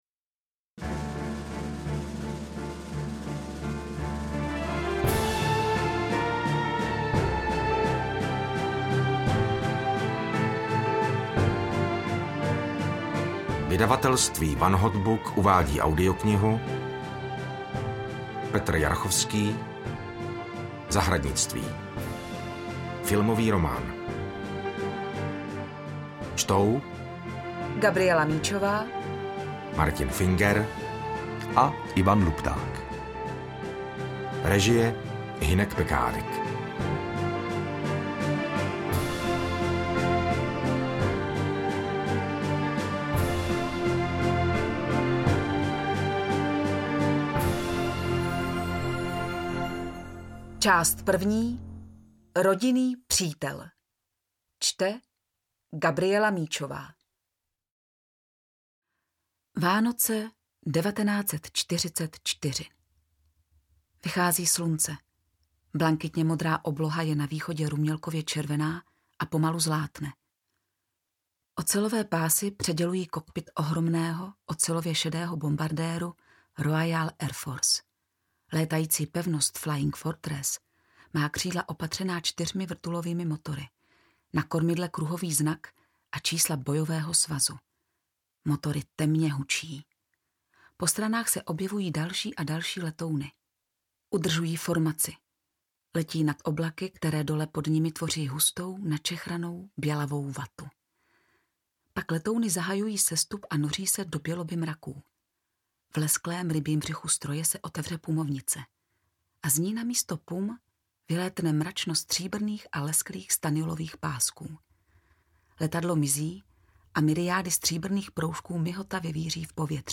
Interpreti:  Martin Finger, Ivan Lupták, Gabriela Míčová
AudioKniha ke stažení, 31 x mp3, délka 10 hod. 19 min., velikost 561,0 MB, česky